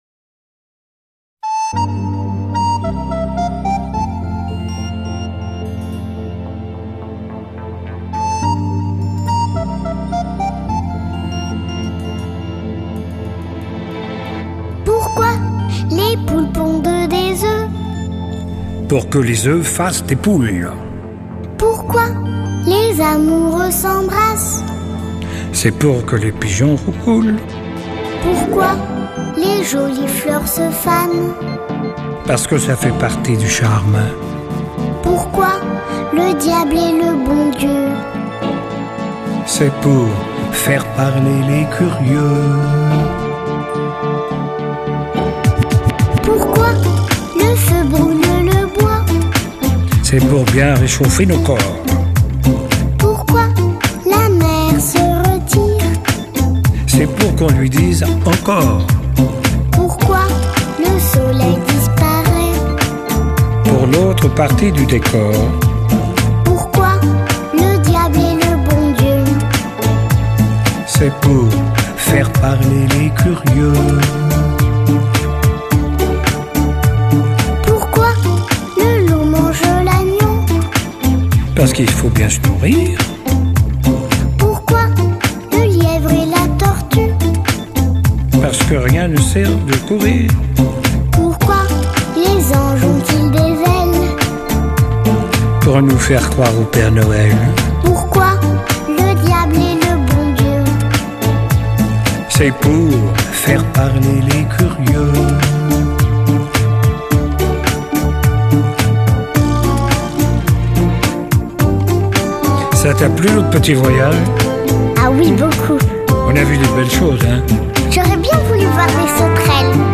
唱片类型：儿童歌曲